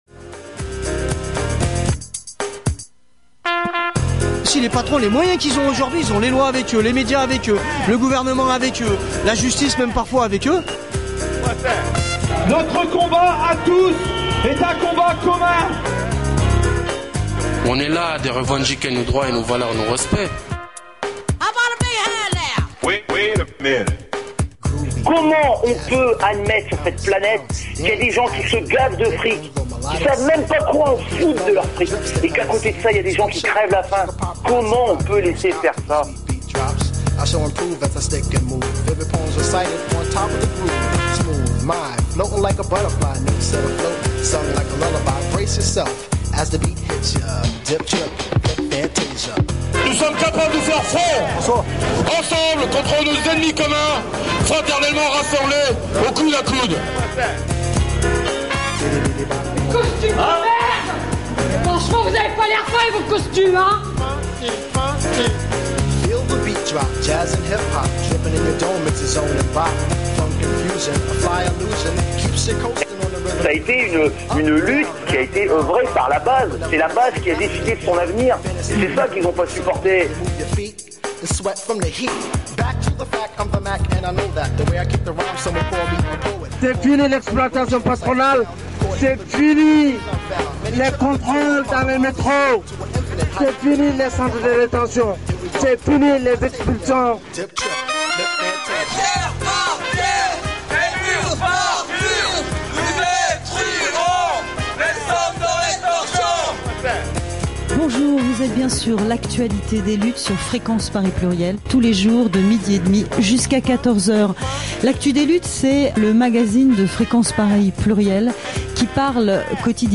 Les premières rencontres des Archives Getaway autour des radios pirates, libres, de lutte… se sont déroulées le 1er juillet 2013 à la Maison Ouverte à Montreuil, en banlieue parisienne.
L’ensemble de la soirée a été enregistrée par la radio FPP qui en a diffusé un montage d’1h30 dans le cadre de l’émission L’actu des luttes.